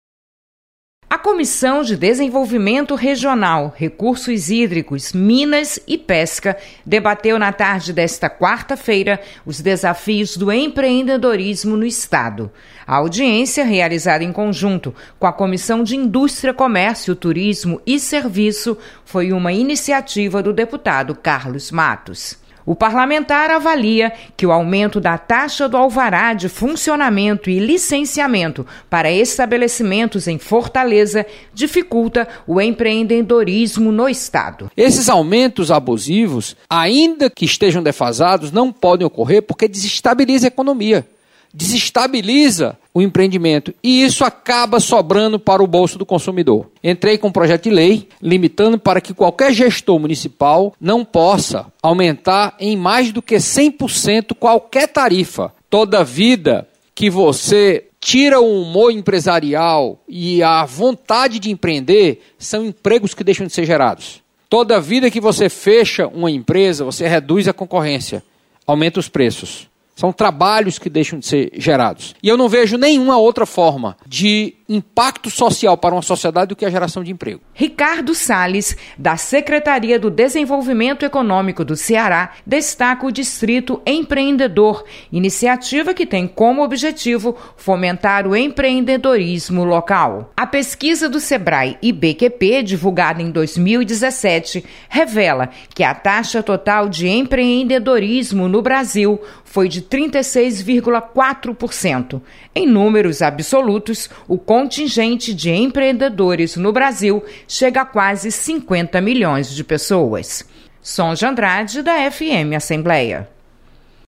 Audiência